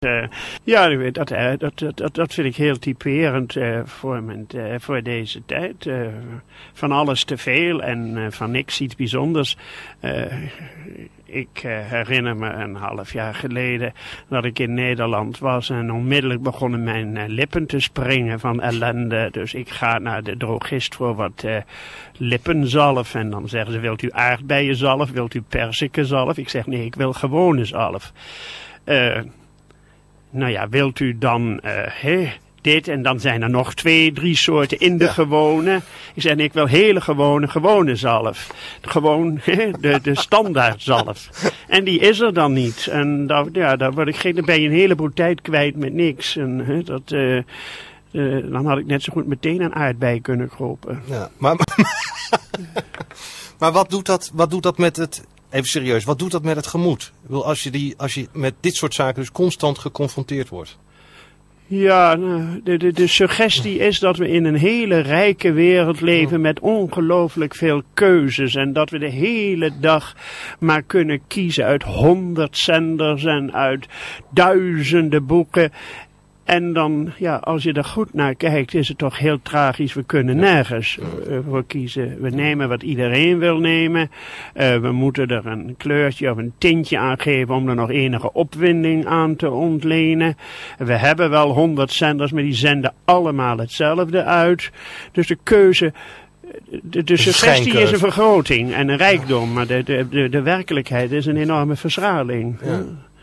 Gerrit Komrij werd zaterdag in het radioprogramma Knetterende Letteren geïnterviewd over zijn schrijverskladboek [real]
komrijinterview.mp3